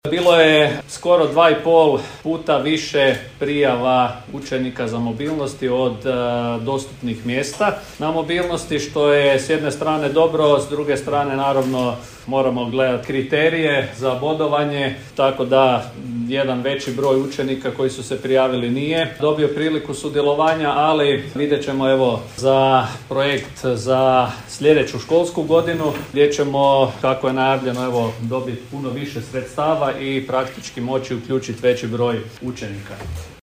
U Strukovnoj školi Đurđevac održana je završna konferencija Erasmus+ projekta „Mreža prilika 2024.”